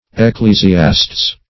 Ecclesiastes \Ec*cle`si*as"tes\, n. [L., fr. Gr.